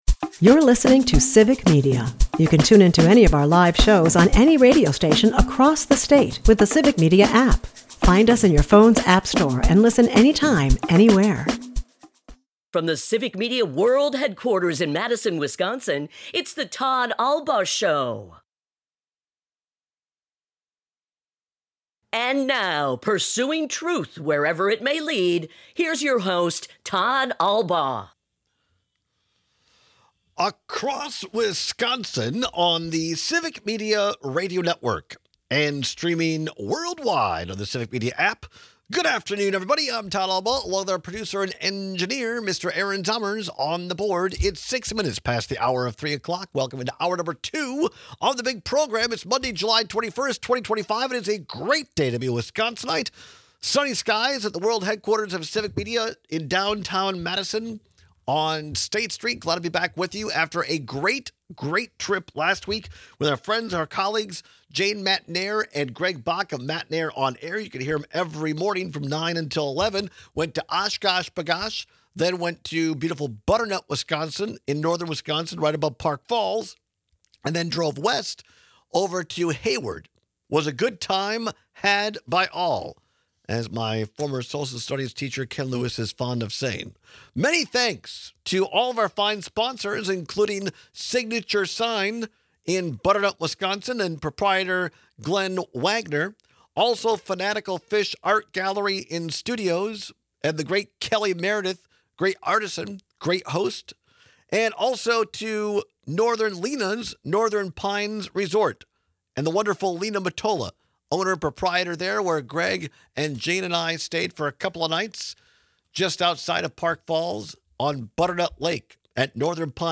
We take some calls and texts on the dangers of gossip and gaslighting alike.